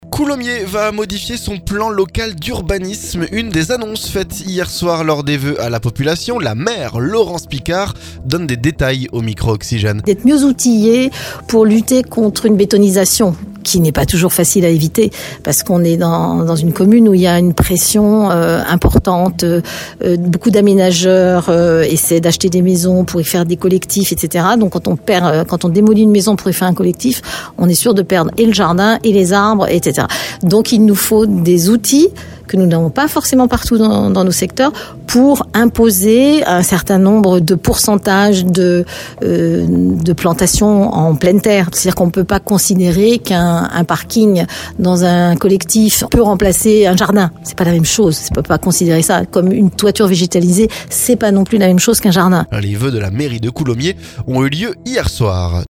La maire, Laurence Picard, donne des détails au micro Oxygène.